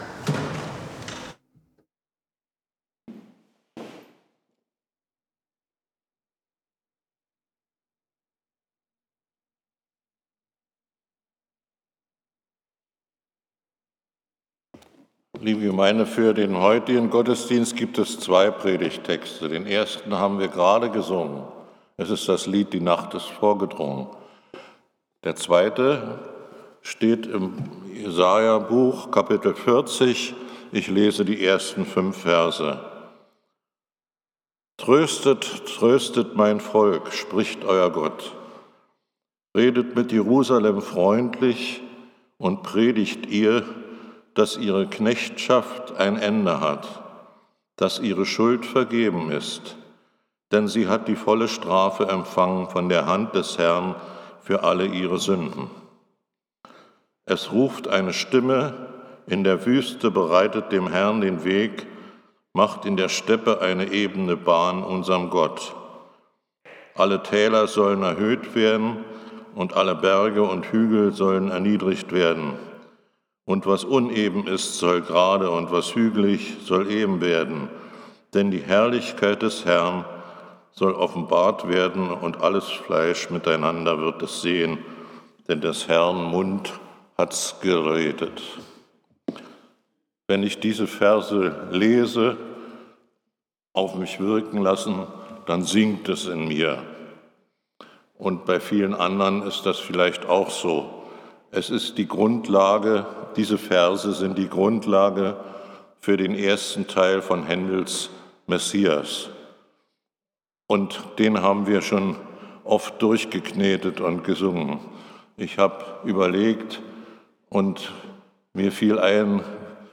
Predigten | Bethel-Gemeinde Berlin Friedrichshain